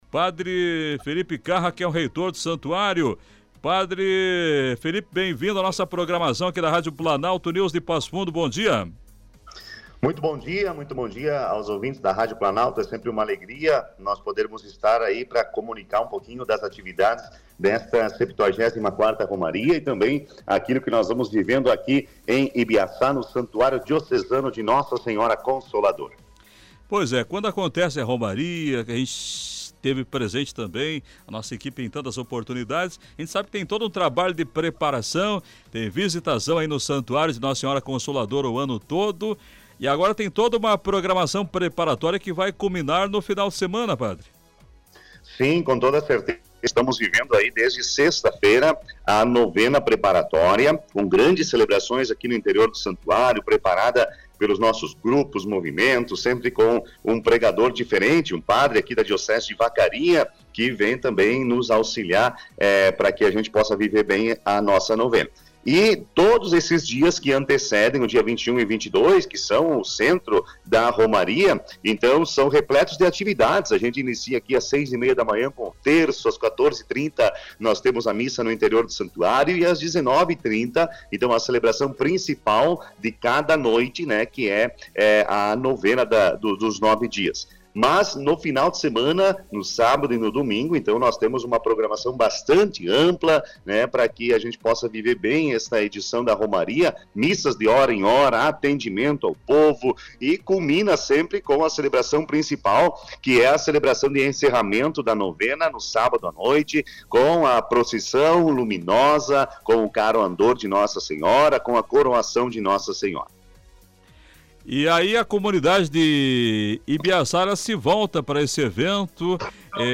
concedeu entrevista ao Grupo Planalto de Comunicação.